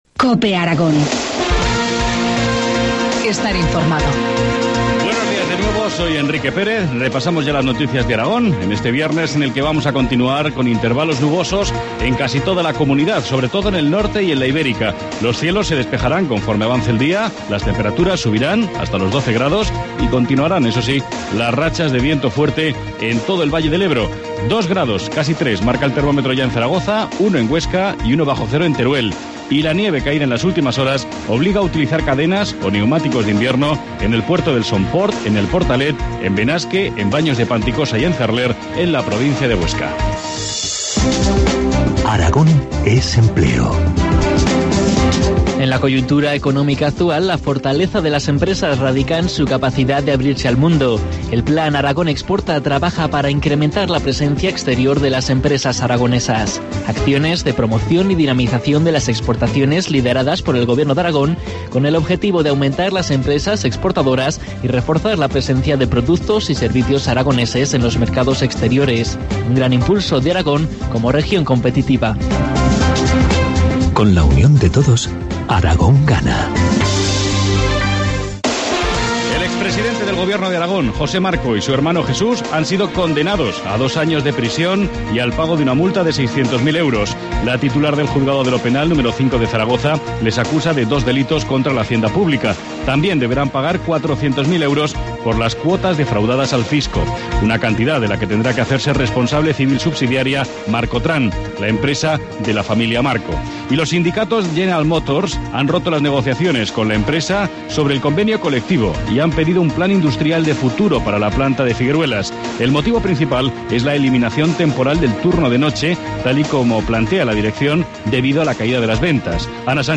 Informativo matinal, viernes 15 de marzo, 8.25 horas